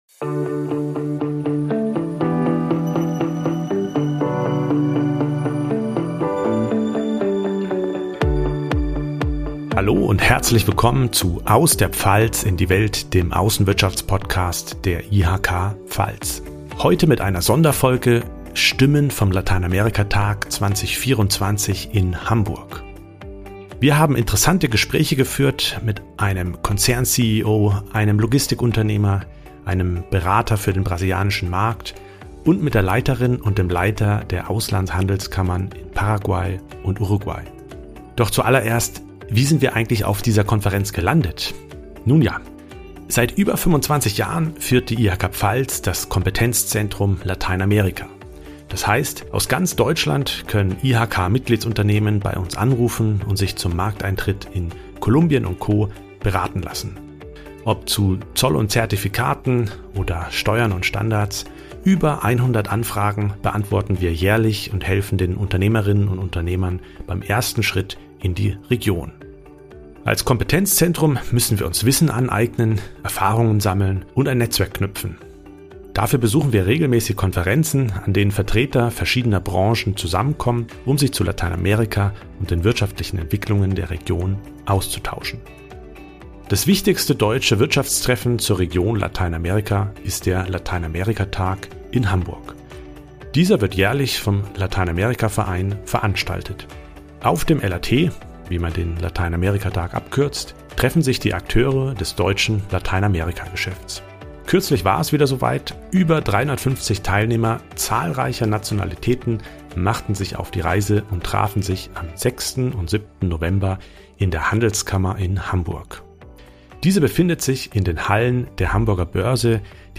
#5 EU-Mercosur-Abkommen: Welche Chancen bietet Lateinamerika? - Stimmen vom Lateinamerikatag in Hamburg ~ IHK-Lateinamerika-Briefing Podcast